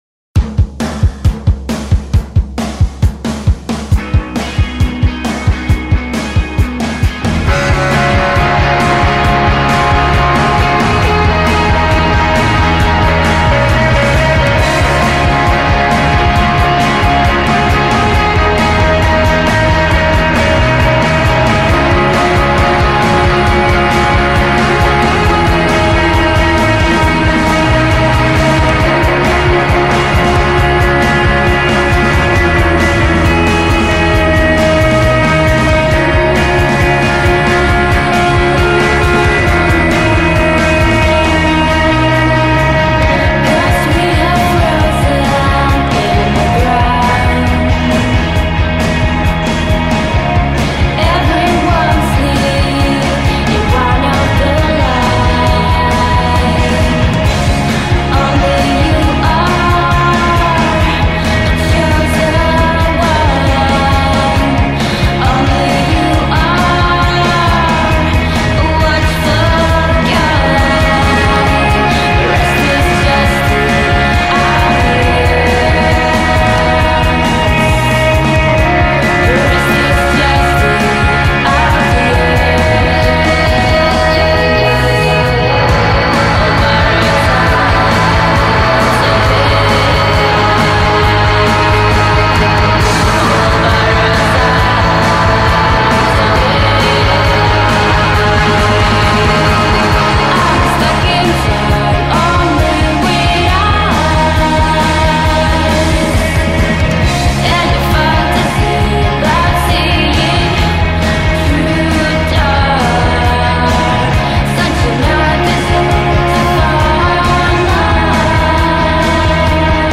The band’s sound is inspired by melancholic pop and […]